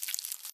Sound / Minecraft / mob / silverfish / step2.ogg
step2.ogg